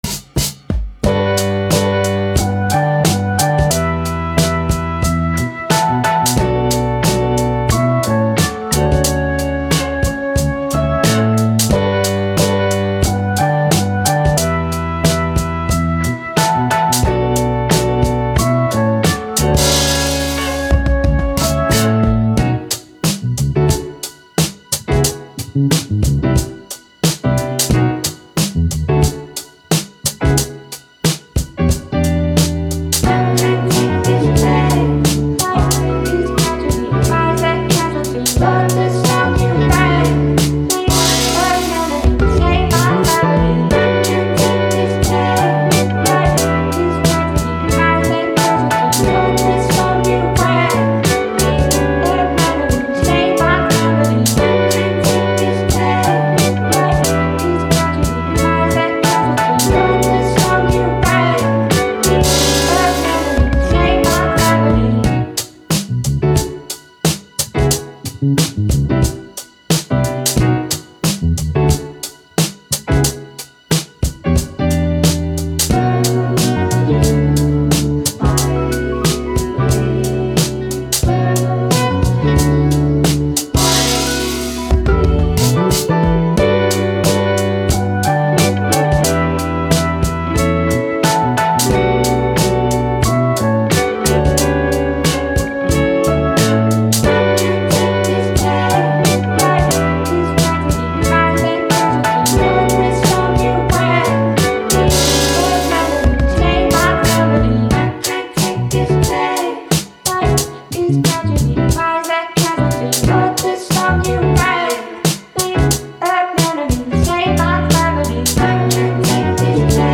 Hip Hop, Boom Bap, Positive, Upbeat, Joy